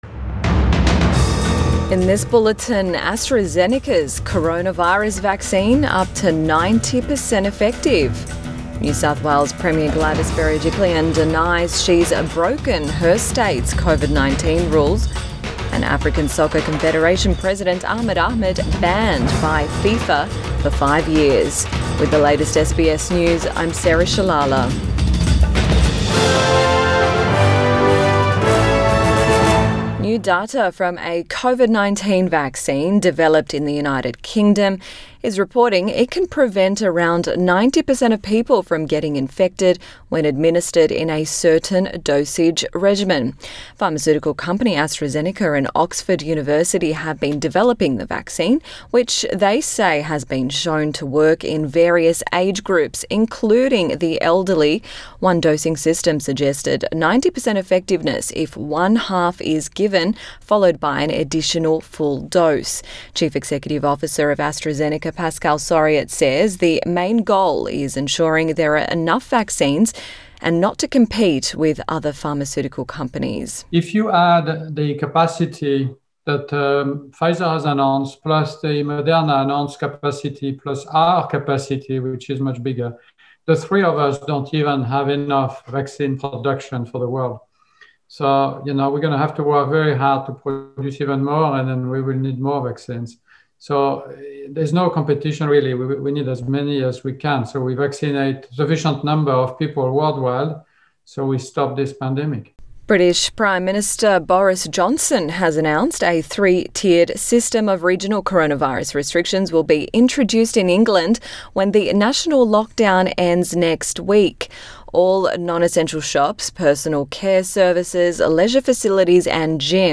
AM bulletin 24 November 2020